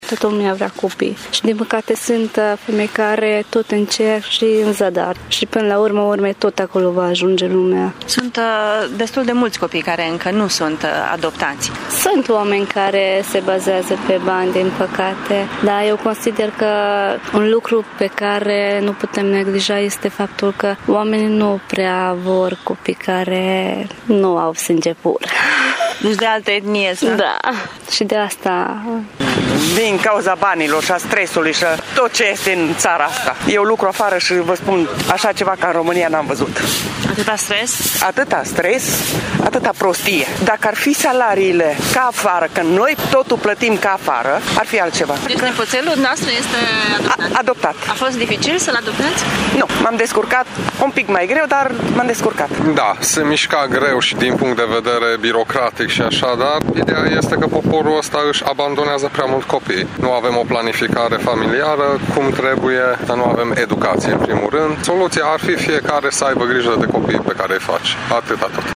Părerile mureșenilor cu privire la adopție sunt împărțite: